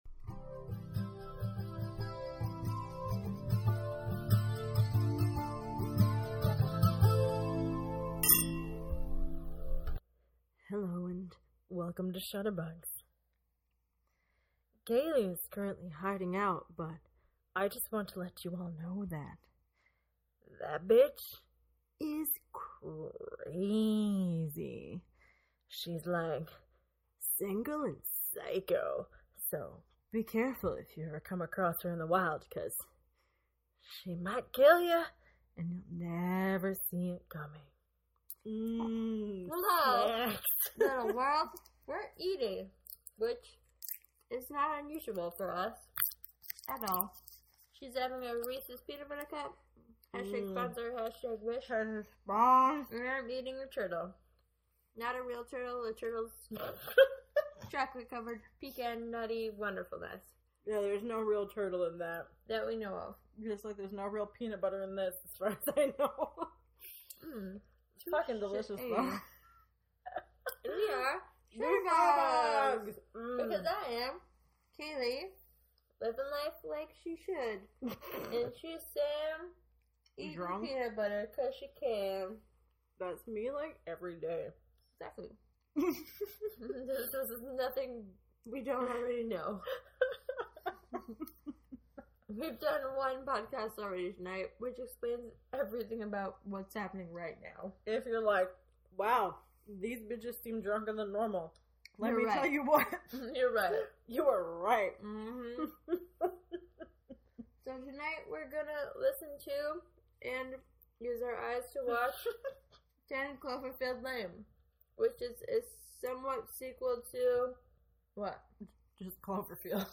We are several drinks in for this one totally stay fully 100% on topic, like always.